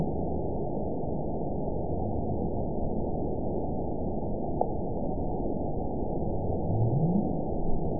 event 921658 date 12/14/24 time 21:56:52 GMT (11 months, 3 weeks ago) score 9.13 location TSS-AB03 detected by nrw target species NRW annotations +NRW Spectrogram: Frequency (kHz) vs. Time (s) audio not available .wav